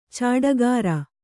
♪ cāḍagāra